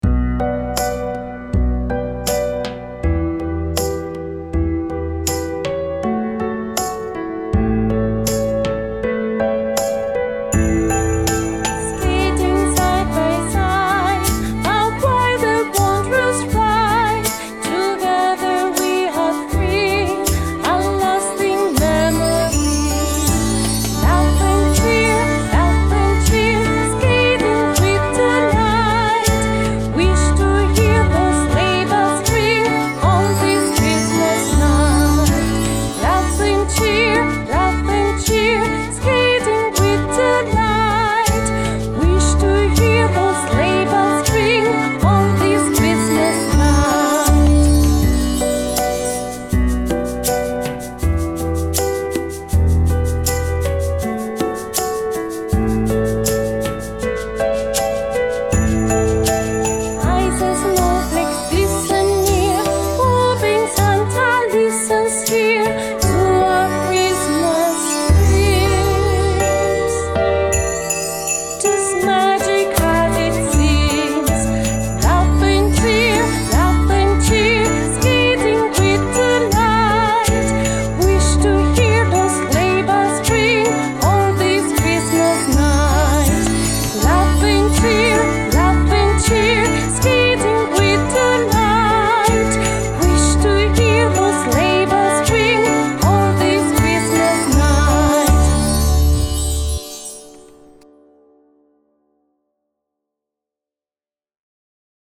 I'm sharing this catchy new Christmas song with you!
7. All instruments and voice